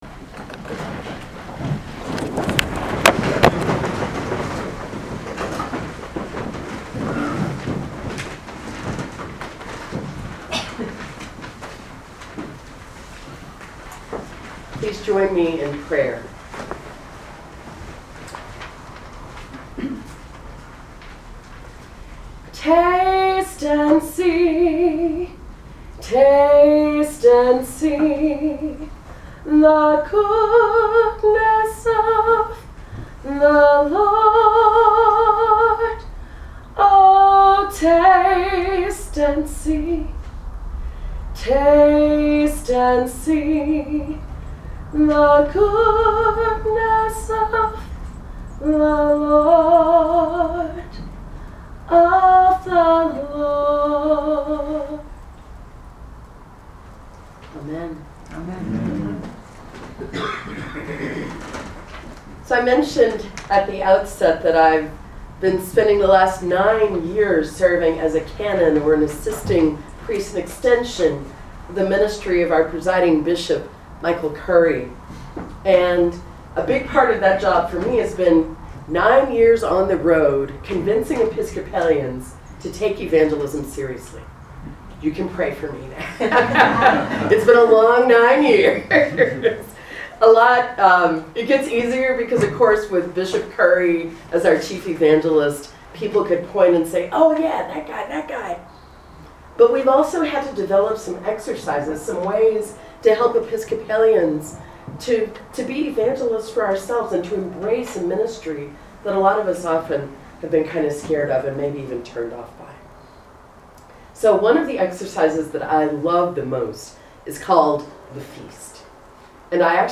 Chapel of the Transfiguration Proper 15
Sermons